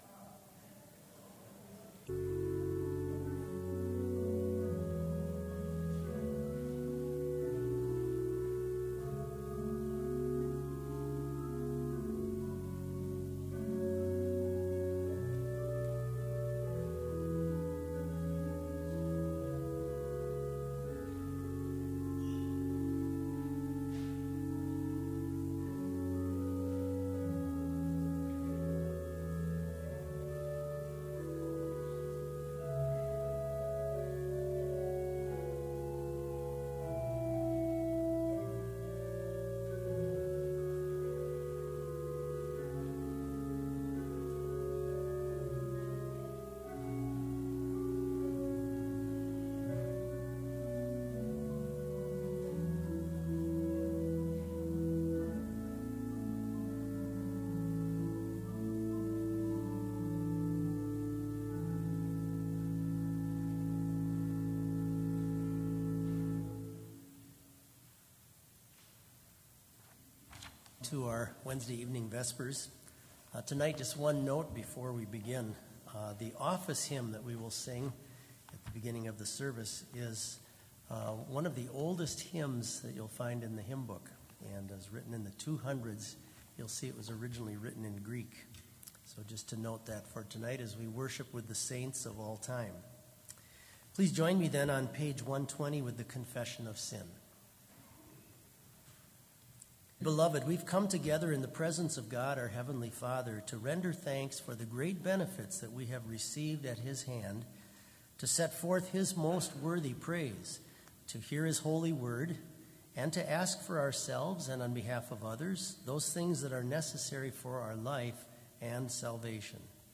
Complete service audio for Vespers - February 13, 2019